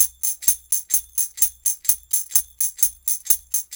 128-TAMB2.wav